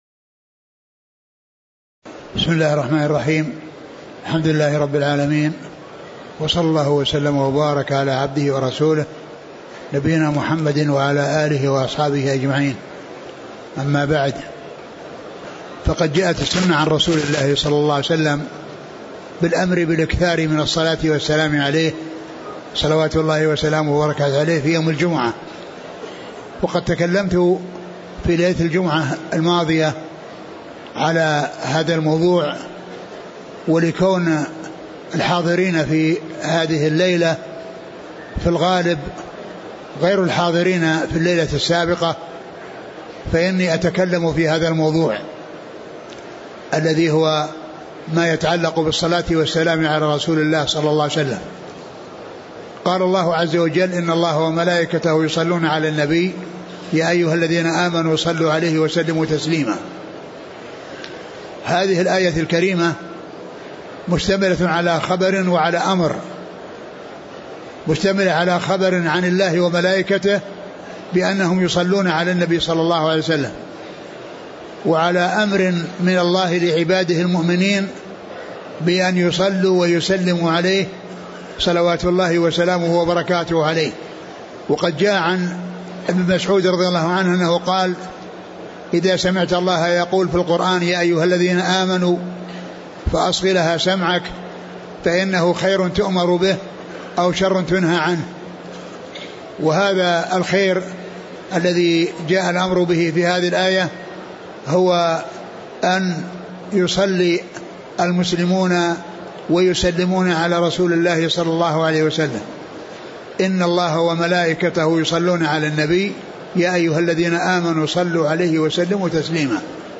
محاضرة الصلاة والسلام على الرسول
تاريخ النشر ٢٣ ذو الحجة ١٤٣٨ المكان: المسجد النبوي الشيخ: فضيلة الشيخ عبدالمحسن بن حمد العباد البدر فضيلة الشيخ عبدالمحسن بن حمد العباد البدر الصلاة والسلام على الرسول 